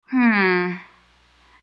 hmm2.wav